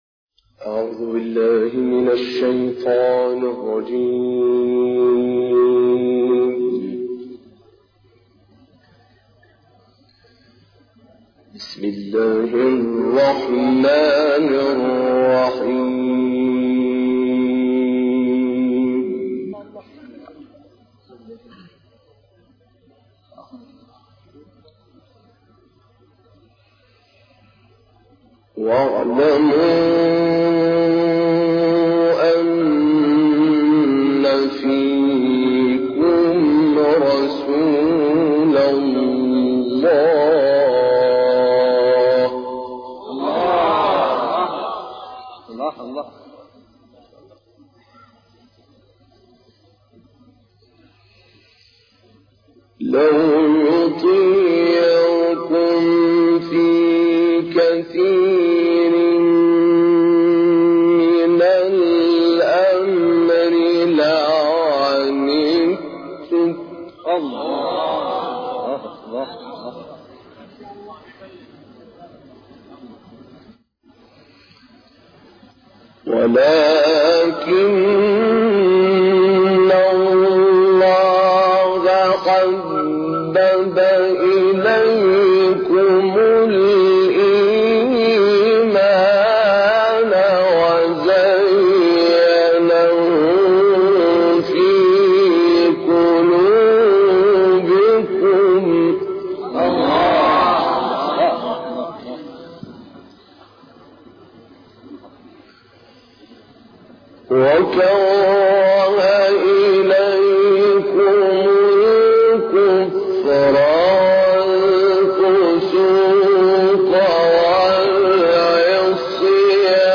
تلاوت «طاروطی» از سوره‌های حجرات و قاف
گروه شبکه اجتماعی: تلاوت آیاتی از سوره‌های حجرات و قاف را با صوت عبدالفتاح طاروطی می‌شنوید.